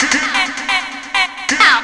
FX 131-BPM 2.wav